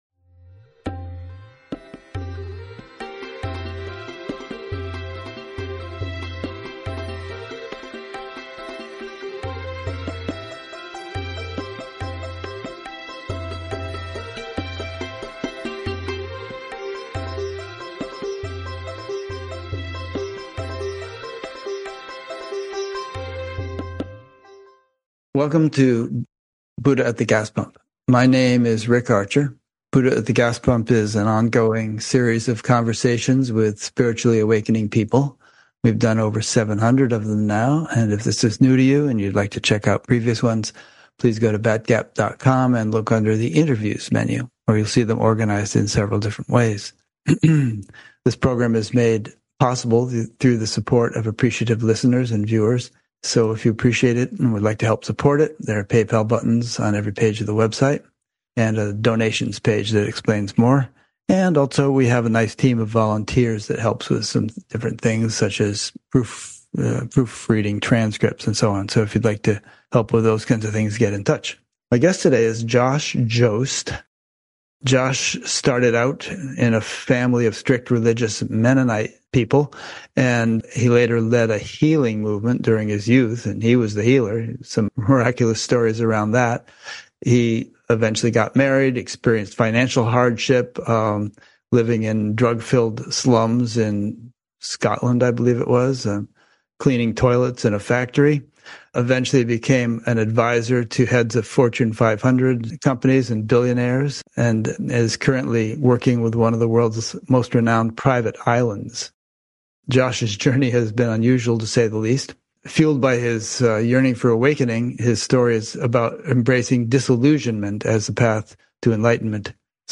Interview recorded April 20, 2024